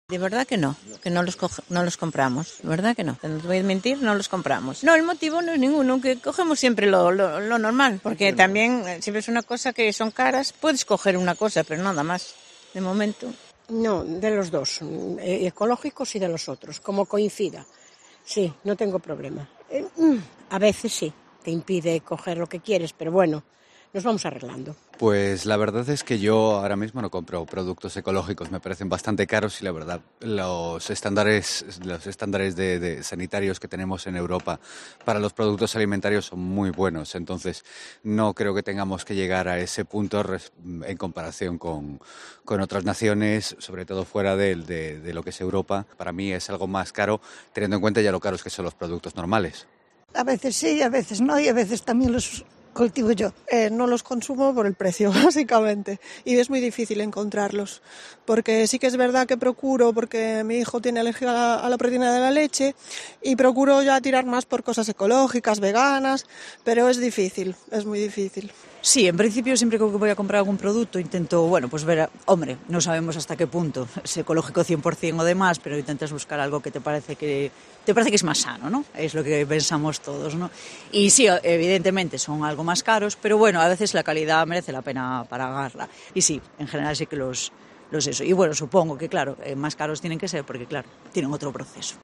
OPINIÓN CIUDADANOS
Los ciudadanos opinaron sobre el consumo de productos ecológicos